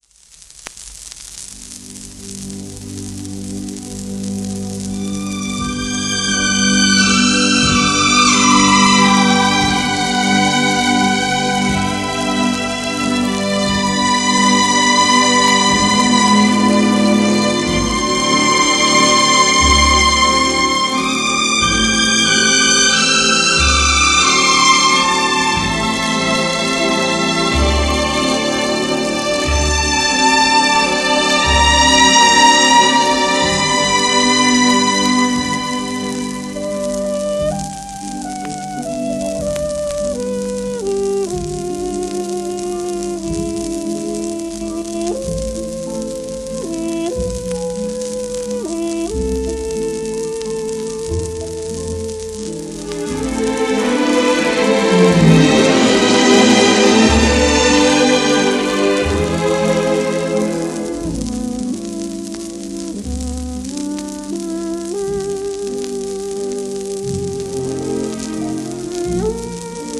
w/オーケストラ